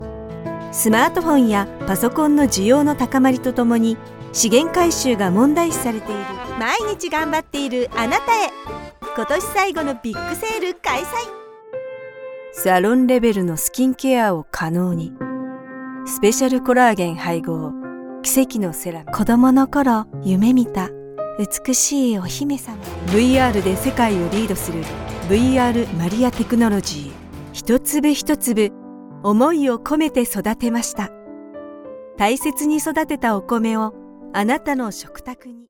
Commercial, Natural, Reliable, Warm, Corporate
Commercial
personable, persuasive, versatile, warm and authentic